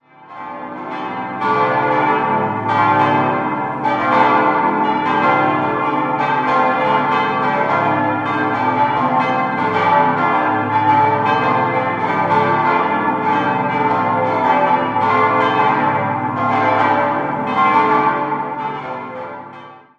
6-stimmiges Geläute: a°-c'-d'-e'-g'-a' Die drei großen Glocken wurden im Jahr 1949 von Karl Czudnochowsky gegossen, die Glocken 5 (1946) und 6 (1948) stammen aus der gleichen Gießerei.